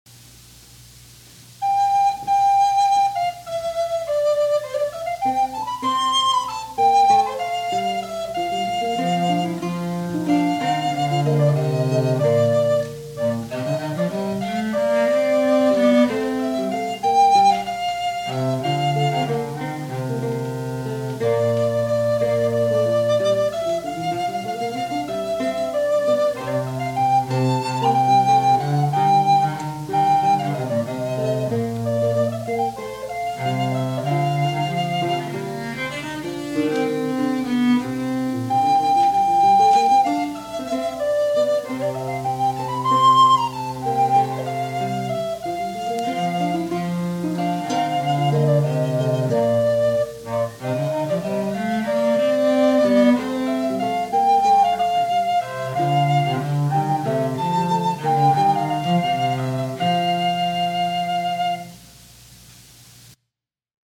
An untexted piece from Petrucci’s Canti A of 1501.